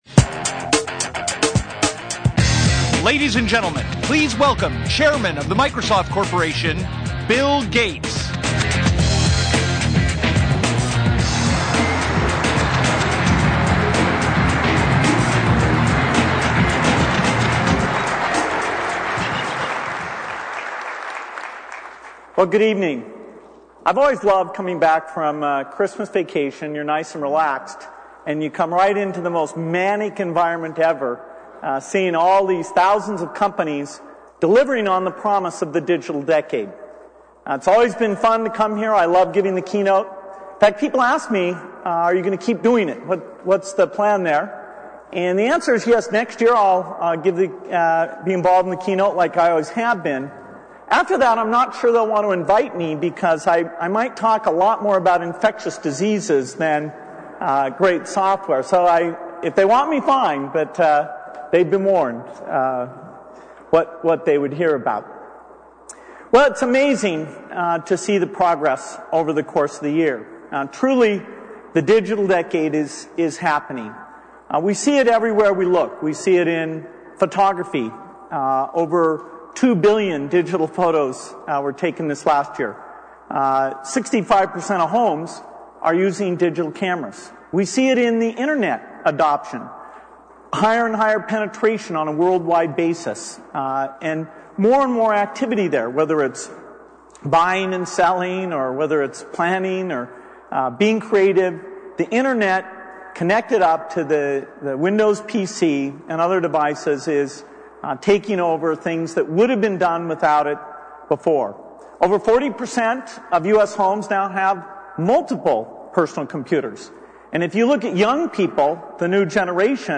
This is the audio from the Bill Gates Keynote from CES 2007.(Note: Due to rights issues, some parts of the program have been replaced with place holder audio)